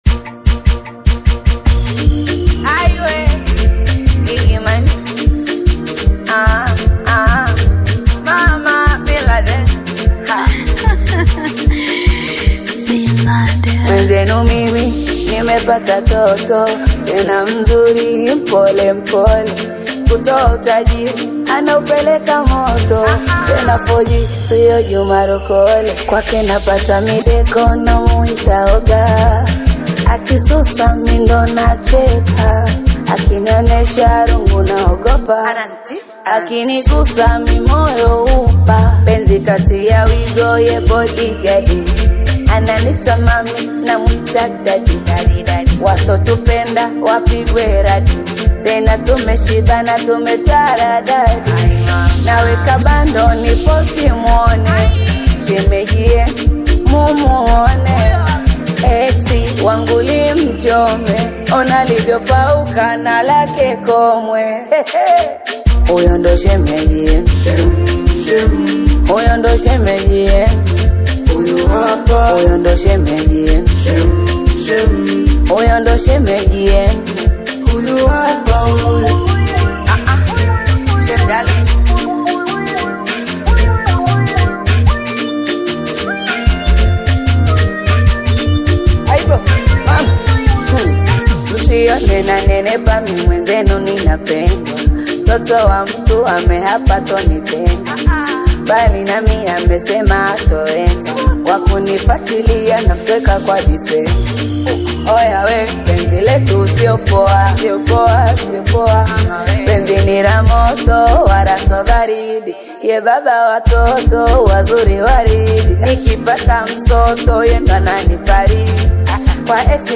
Singeli music track
Tanzanian Bongo Flava artist, singer, and songwriter